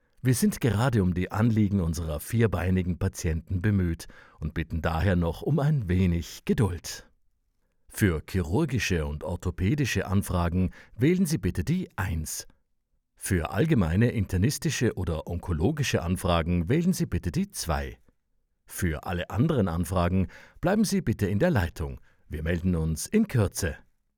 Stimme für Anrufbeantworter (DE/EN/FR)
Telefonsystem Tierarztpraxis: